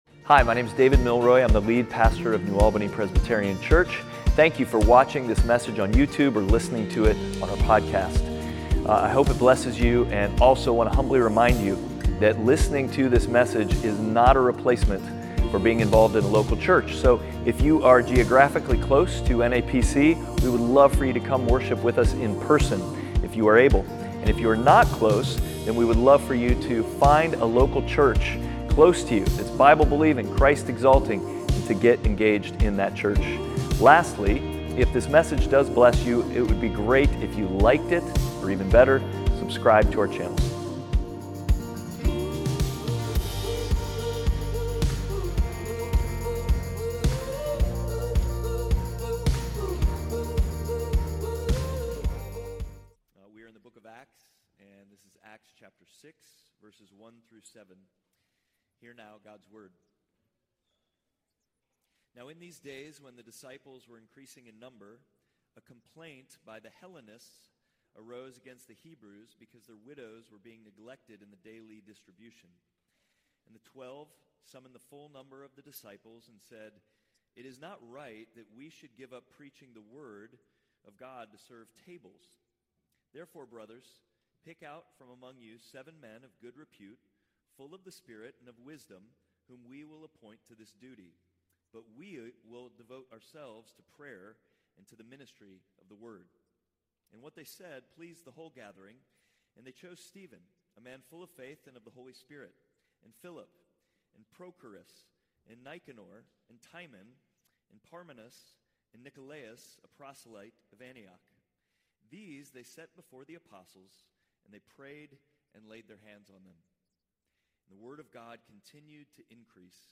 Outward Passage: Acts 6: 1-7 Service Type: Sunday Worship « Outward